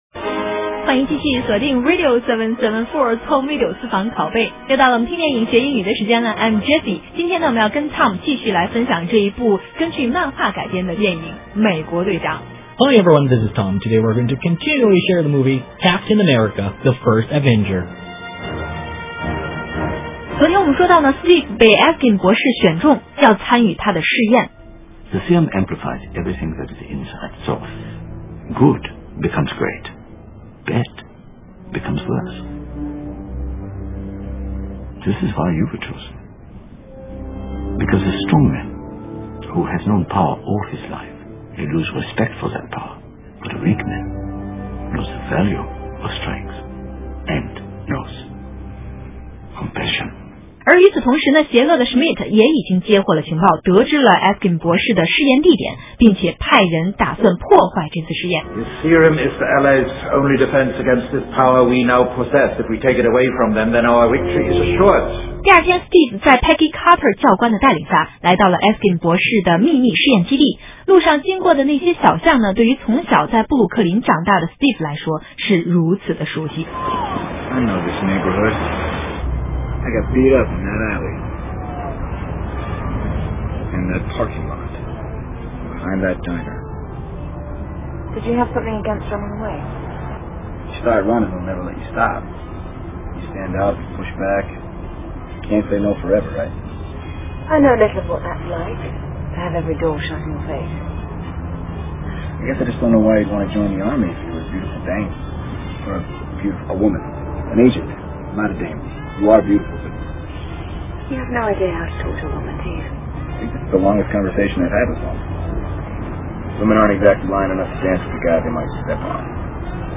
Home Video私房拷贝 第248期:美国队长(2)MP3音频下载,私房拷贝 Home Video，通过欣赏和讲解英文电影原声，为英语学习者搭建锻炼听力的平台，同时进一步了解欧美文化和生活习俗。